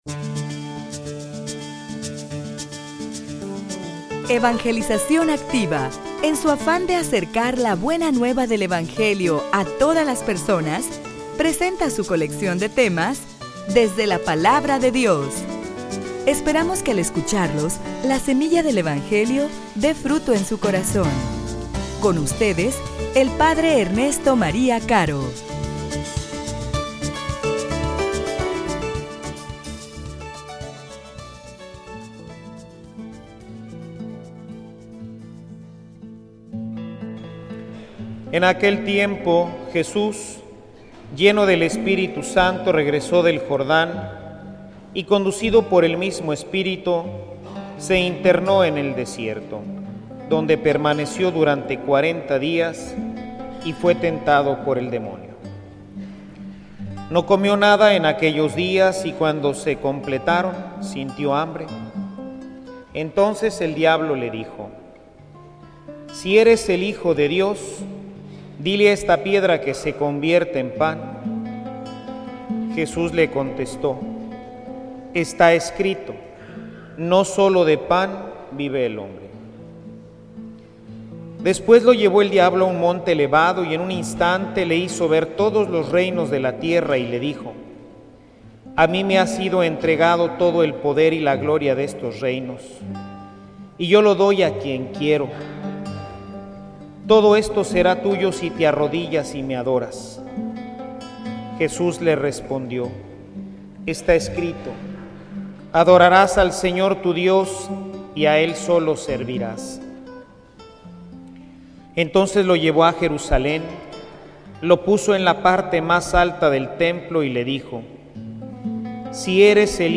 homilia_Las_Tentaciones.mp3